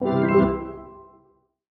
openwindow.mp3